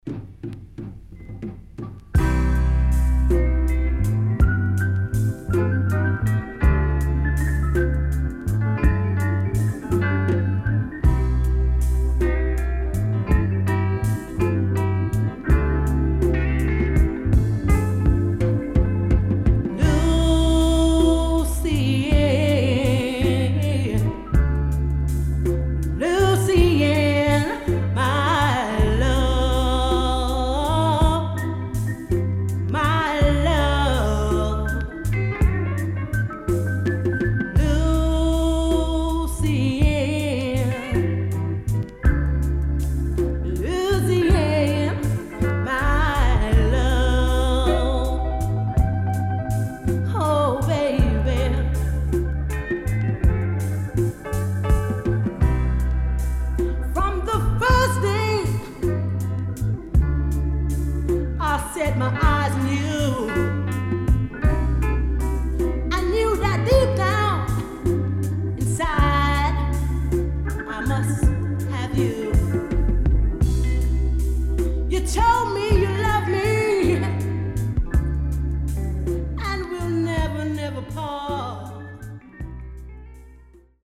Nice Female Lovers & Ballad